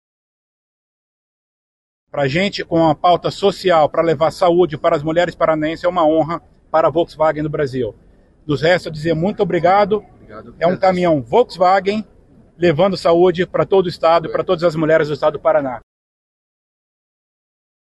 Durante o evento de apresentação da Carreta